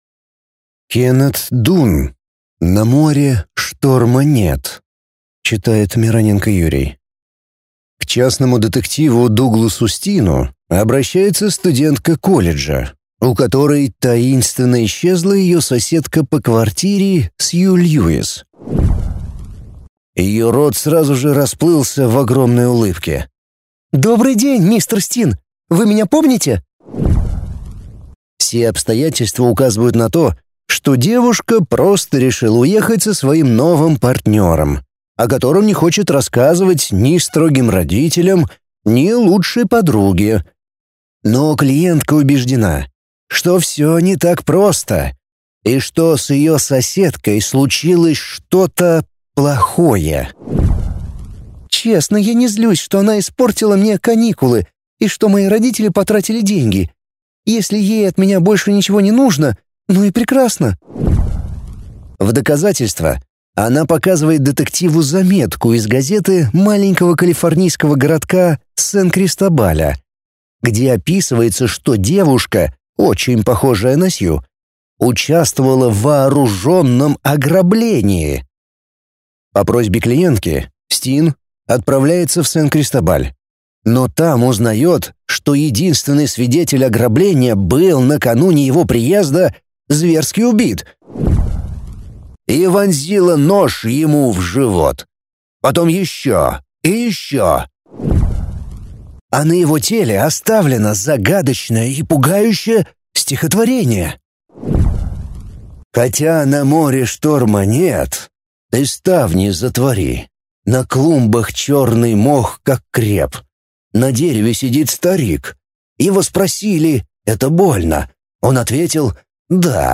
Аудиокнига На море шторма нет | Библиотека аудиокниг